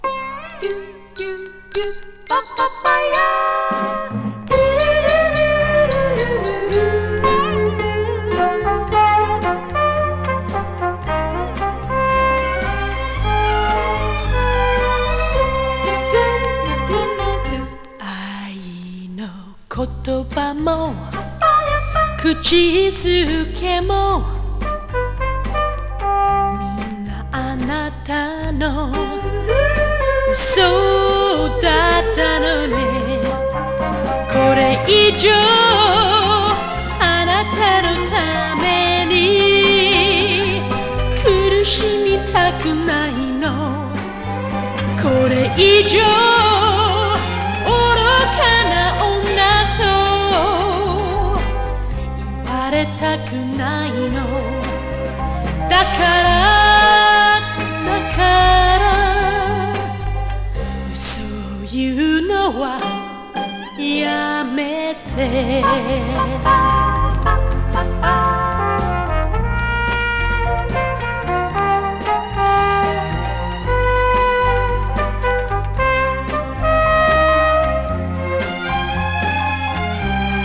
情熱的でソウルフルな歌声が魅力！
情熱的なヴォーカルと、ファンキーな演奏がエコーに巻かれて見事にフィットしてます。
ミッドテンポのロッキン・ダンサブル・ナンバー！
こぶしが効きつつ妖艶な歌声が魅力的な昭和歌謡！